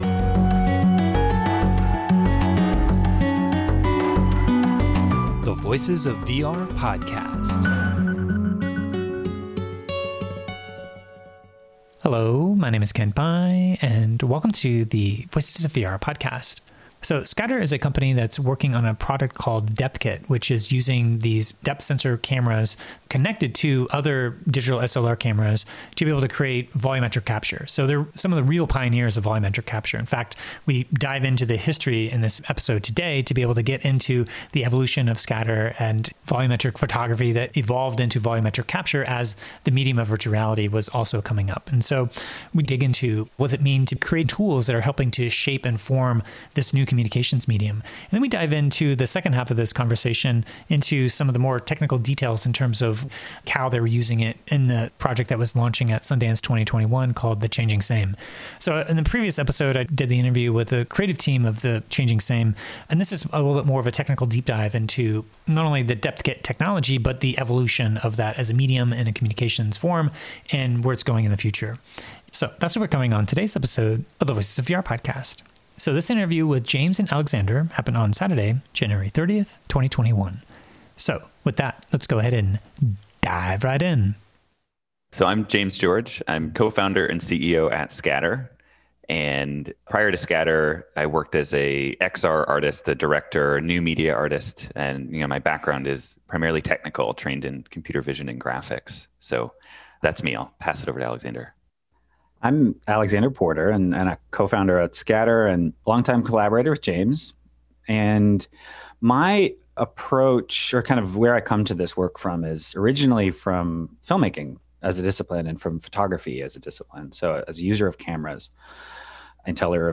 In the second half of this in-depth interview, we do a technical deep dive to look at some of the pragmatic challenges and open problems when it comes to integrating volumetric capture within an independent immersive storytelling project. We talk about Scatter’s intention of democratizing access to the tools of volumetric filmmaking and the decision to move from a scrappy open source project to a fully-fledged start-up company with funding and customers.